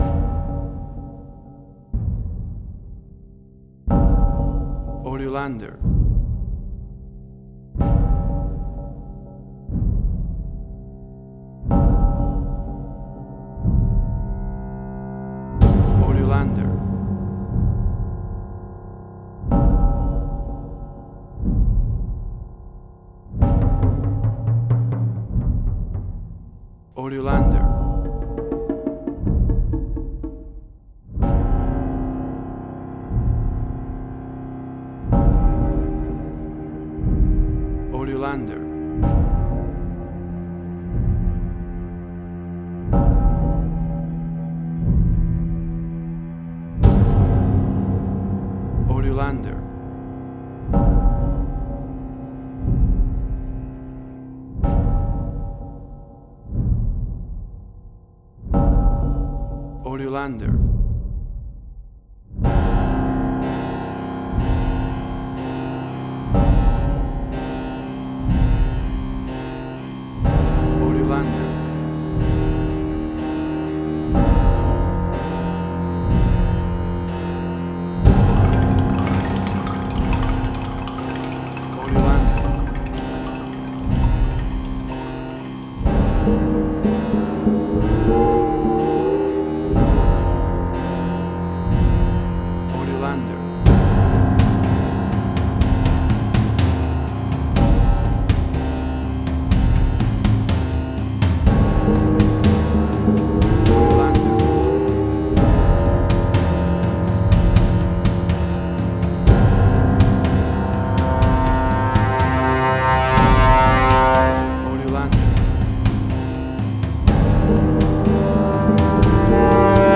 Spaghetti Western
Tempo (BPM): 61